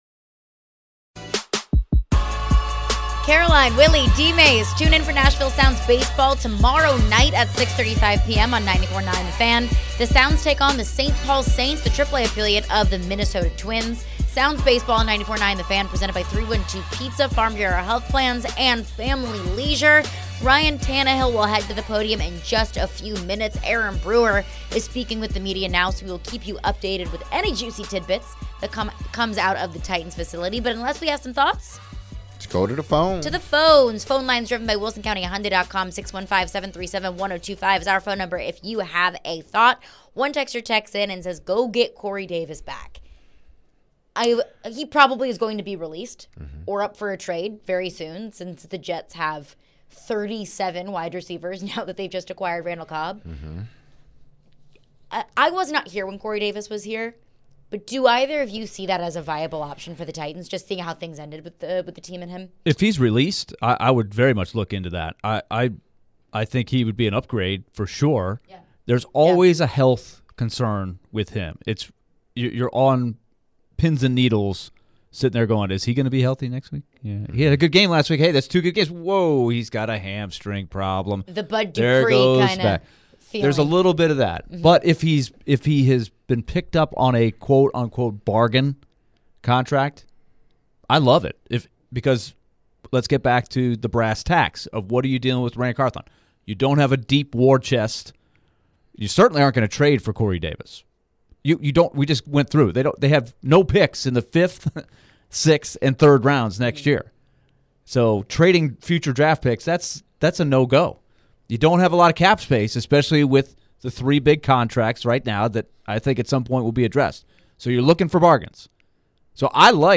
Ryan Tannehill Presser (5-3-23)
Tennessee Titans QB Ryan Tannehill spoke to the media after the 2023 NFL Draft.